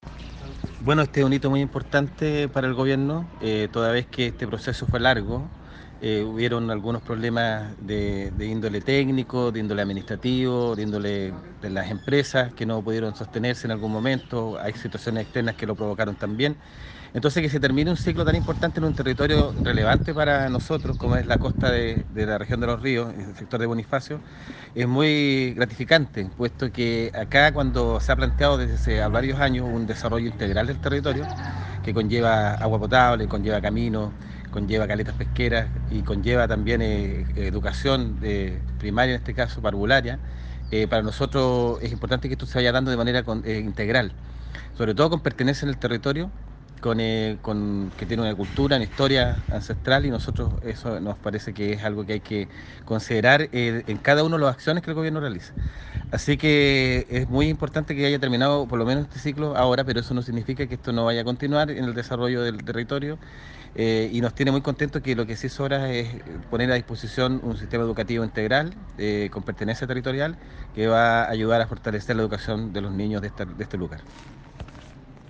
cuna-delegado-presidencial-regional-jorge-alvial.mp3